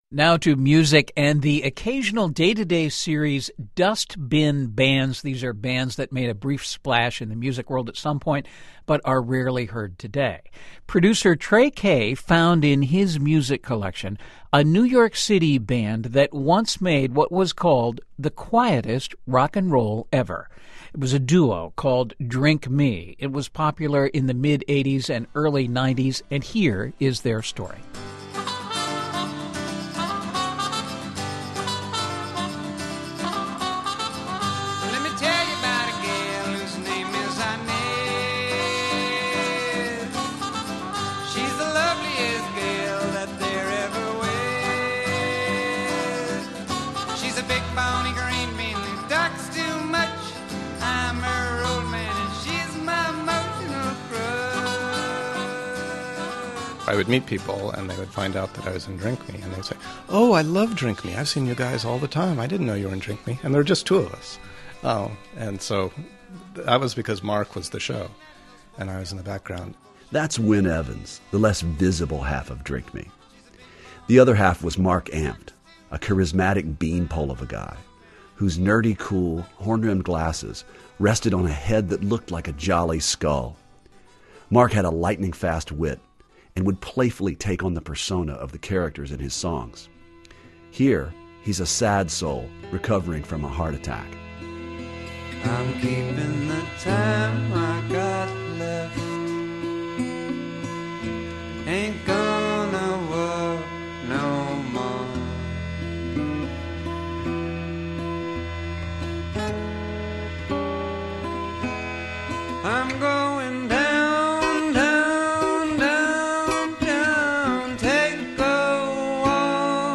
Listen to NPR’s “Dust-bin Bands” segment on 90’s Brooklyn duo Drink Me, the “dust-bin band” closest to my heart as well as my all-time favorite guitar-and-Fanta-bottle duo.
Drink Me’s shtick is hard to describe but they sounded a little like Simon & Garfunkel with a sense of humor.